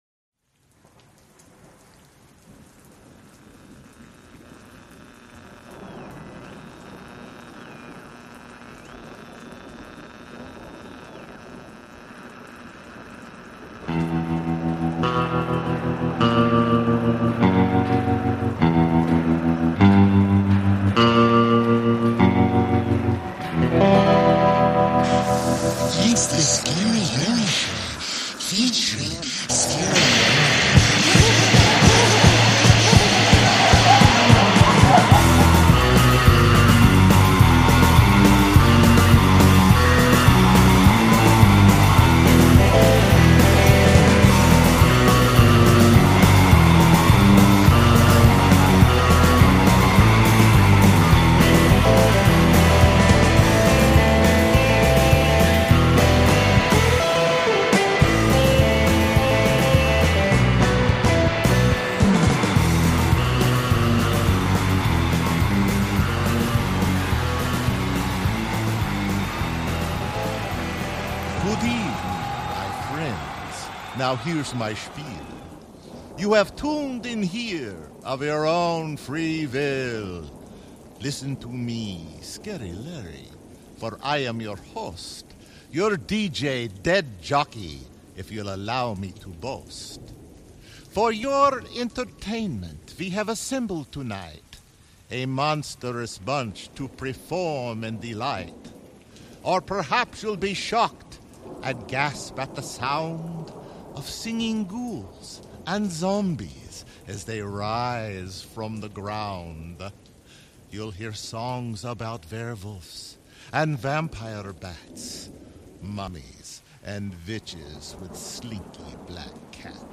From punk to rock and everything in between, this retro "Death-Jockey" delivers the tricks and treats that will have you howling at the moon.